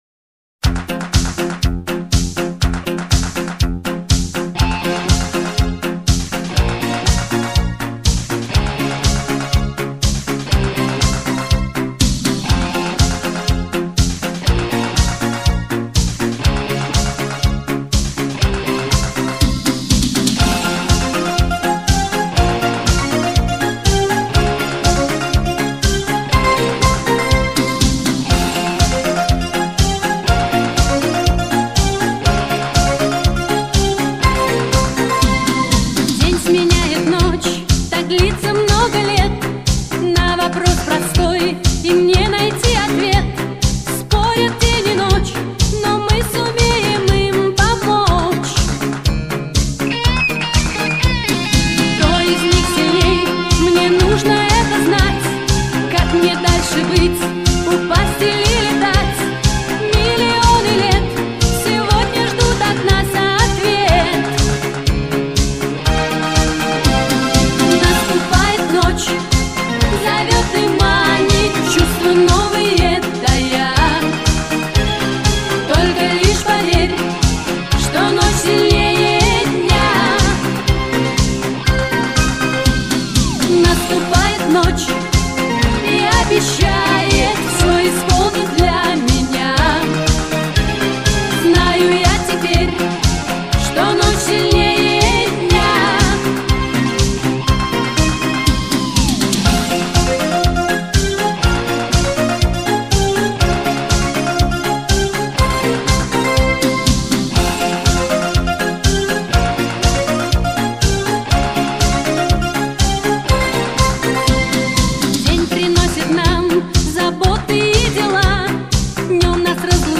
меццо-сопрано.
сопрано..